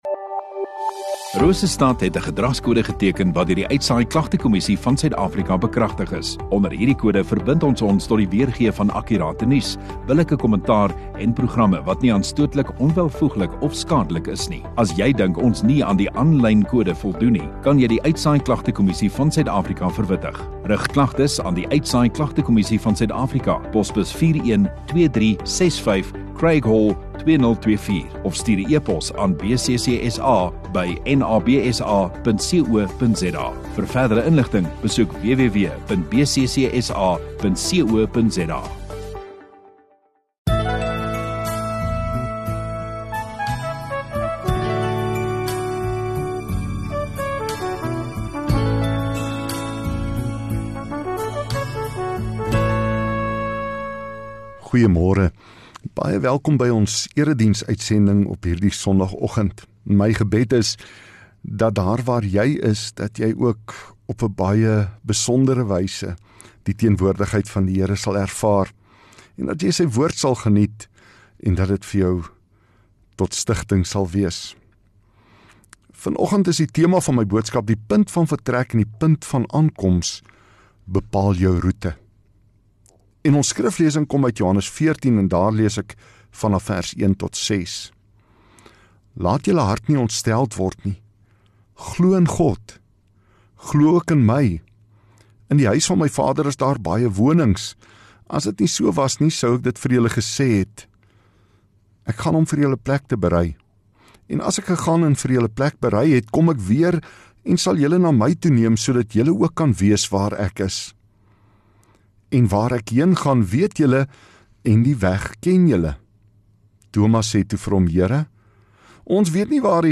28 Apr Sondagoggend Erediens